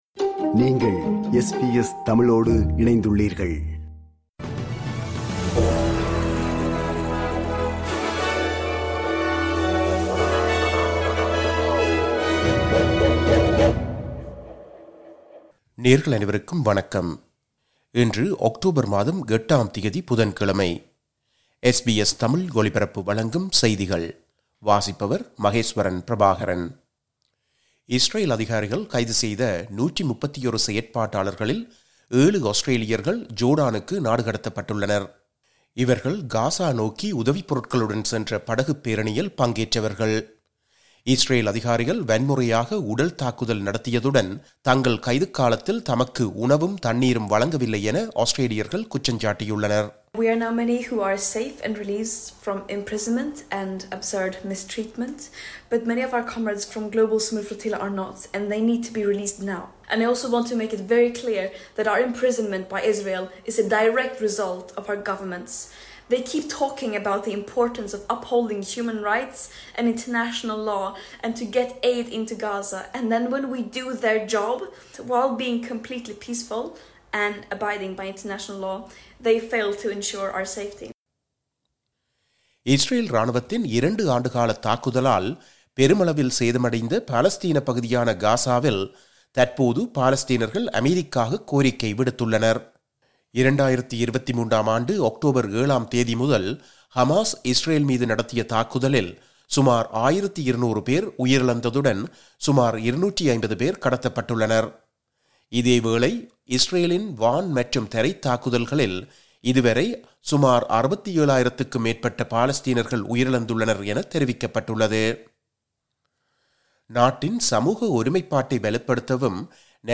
இன்றைய செய்திகள்: 08 அக்டோபர் 2025 புதன்கிழமை
SBS தமிழ் ஒலிபரப்பின் இன்றைய (புதன்கிழமை 08/10/2025) செய்திகள்.